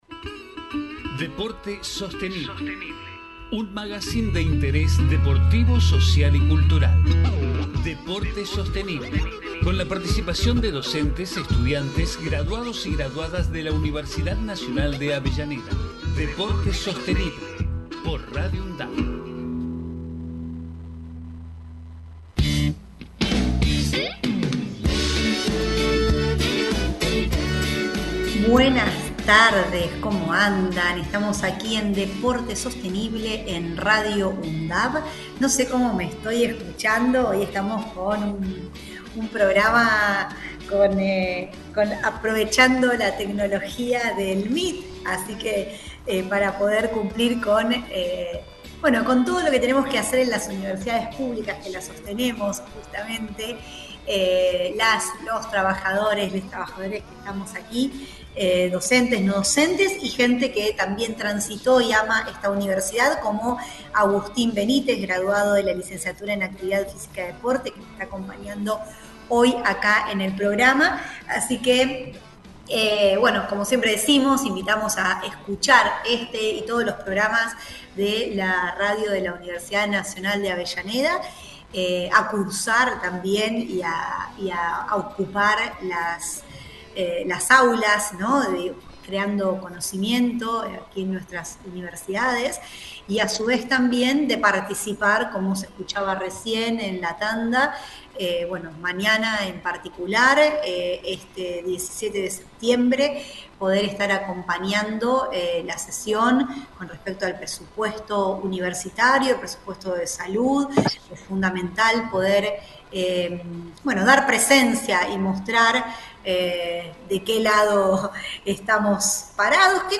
Magazine de interés deportivo, social y cultural que se emite desde septiembre de 2012.